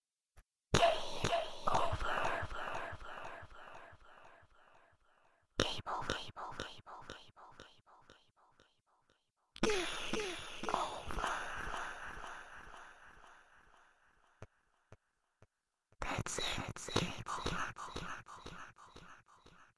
Ghost saying voice actor